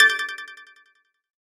whisper.mp3